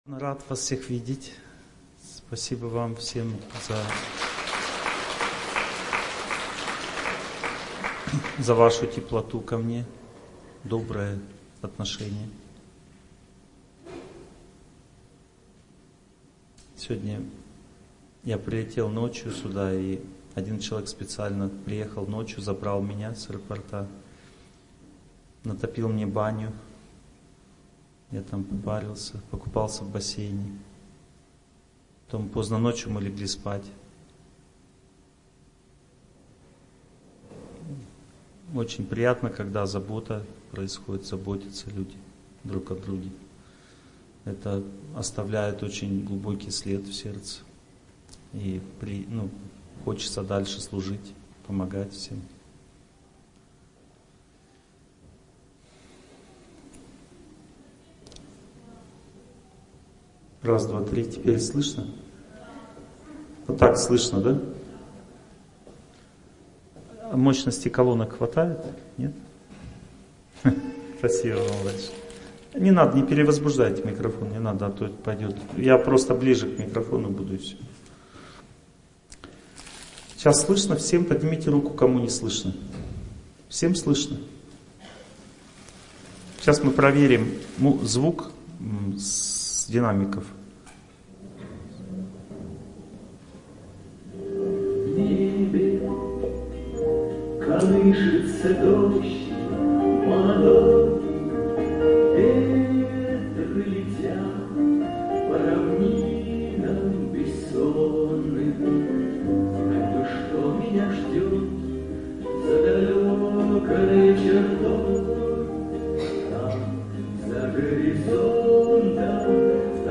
Znaki-sudbi-Kak-ponyat-chto-menya-zhdyot-Lekciya-1.mp3